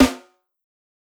SNARE_VPUNCH.wav